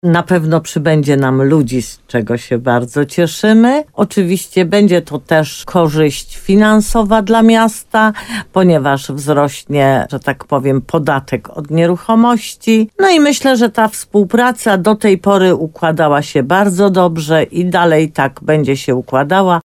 W programie Słowo za Słowo na antenie RDN Nowy Sącz burmistrz podkreślała, że obecność wojska podniesie rangę miasta.